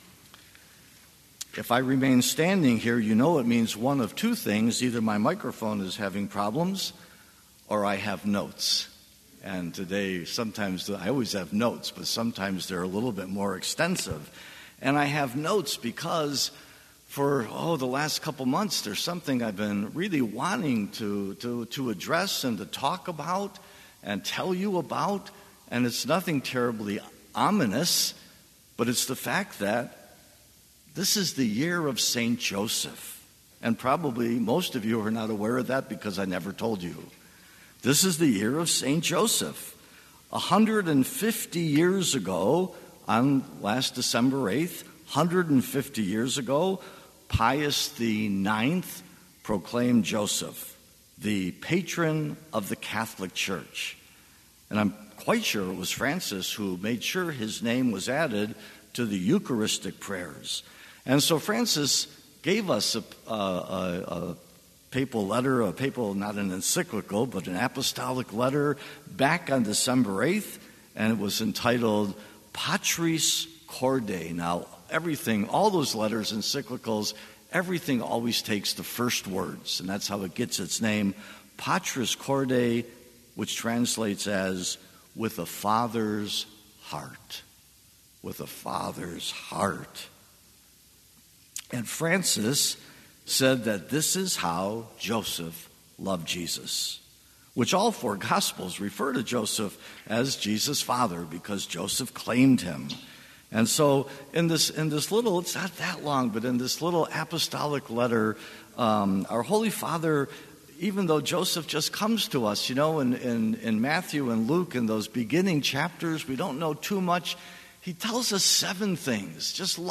2021 St. Robert of Newminster Parish, Ada MI Visit http